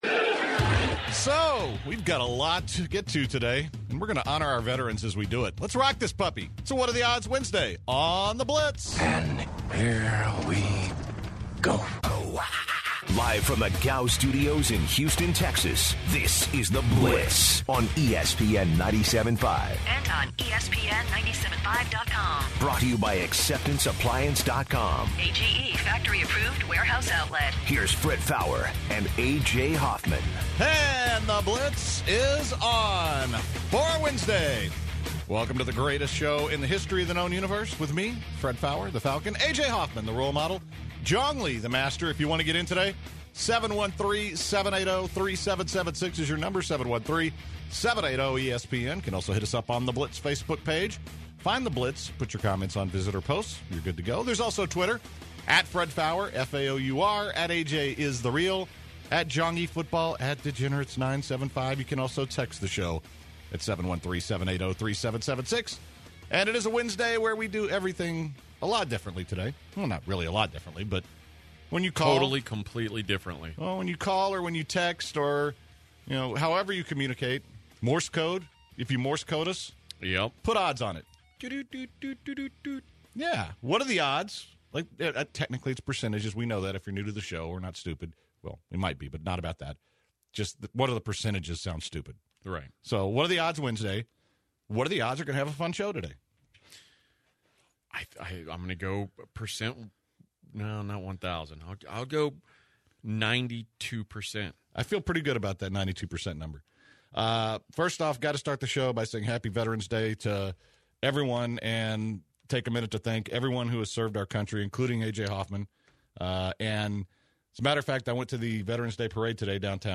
Kimbo Slice and Royce Gracie come in studio to talk about their fights at the Toyota Center February 19th.